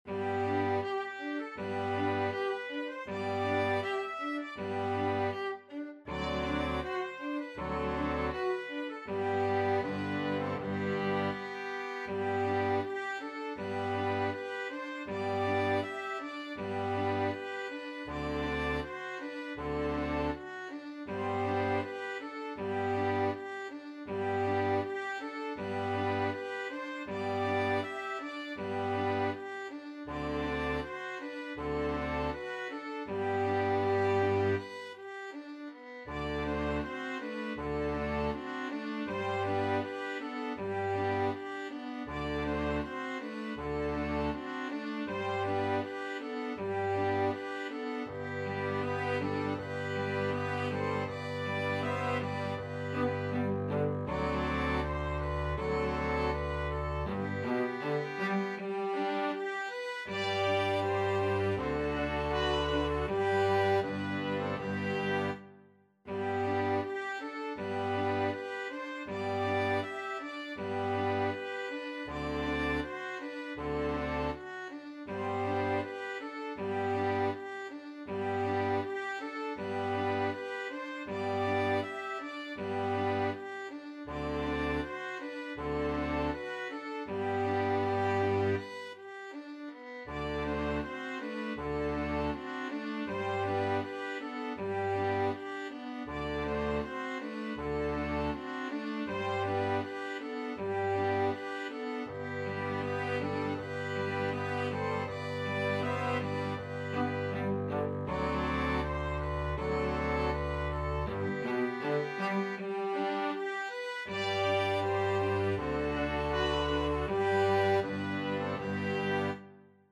Free Sheet music for Flexible Mixed Ensemble - 5 Players
TrumpetViolin
Violin
Viola
BassoonTromboneCello
BassoonDouble Bass
Traditional Music of unknown author.
Andante
4/4 (View more 4/4 Music)